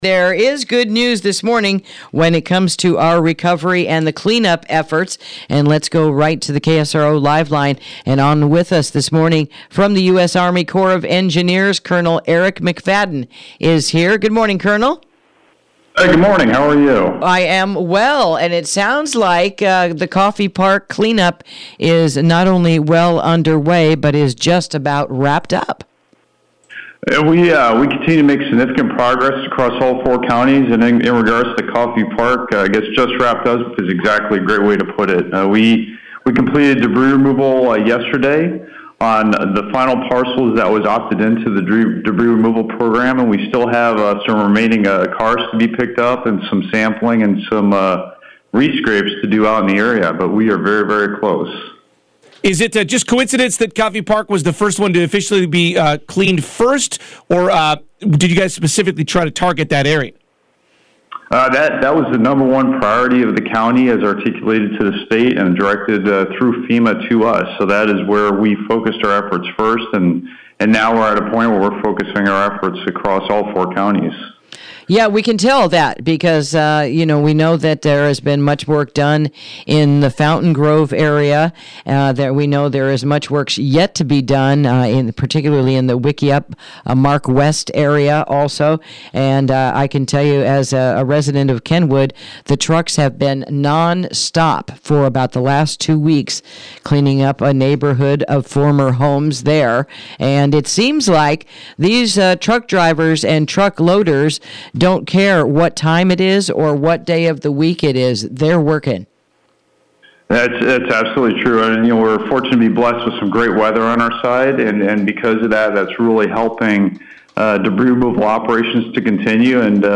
Interview: Clean Up Rebuild Efforts Update from the Army Corps of Engineers